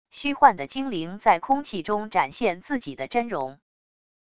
虚幻的精灵在空气中展现自己的真容wav音频